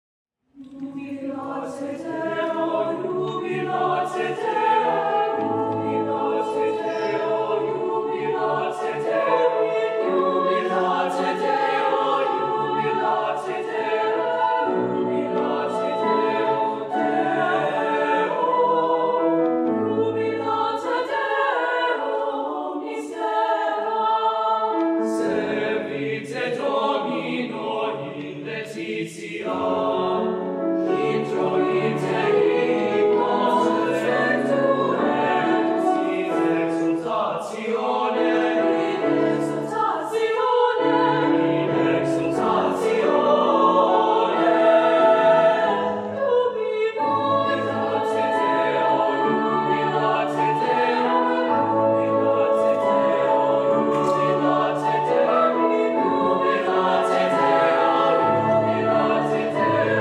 four curricular choirs and two a cappella ensembles
Sing With Heart: Spring Concert, 2019
With: Mustang Chorale